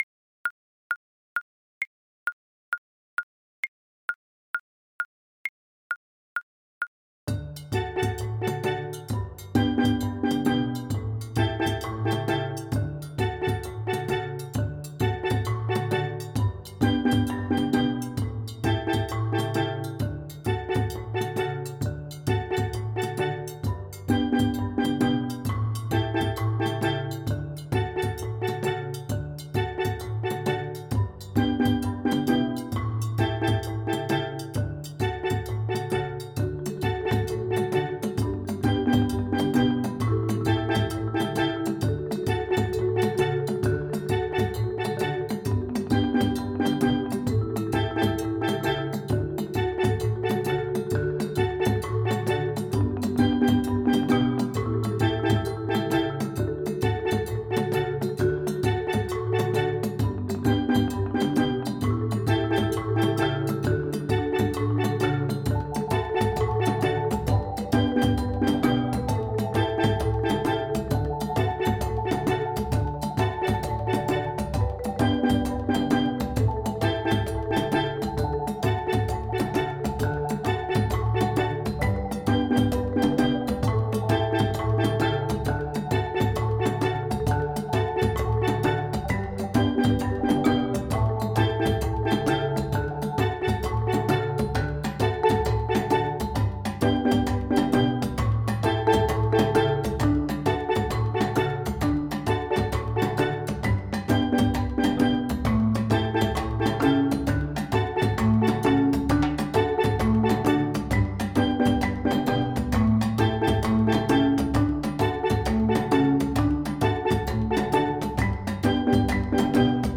4/4 (View more 4/4 Music)
D major (Sounding Pitch) (View more D major Music for Choir )
Choir  (View more Beginners Choir Music)
World (View more World Choir Music)
Congolese